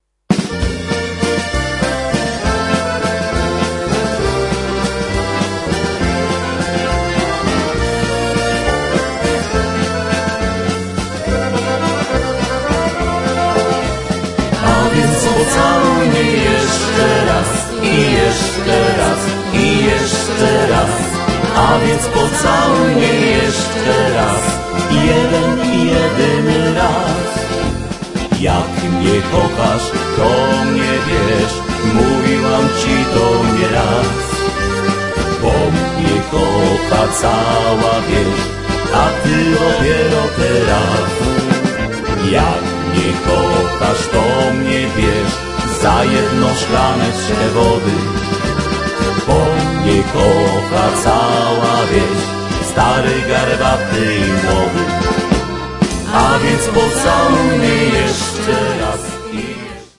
Vocals
Accordion
Percussion
Guitars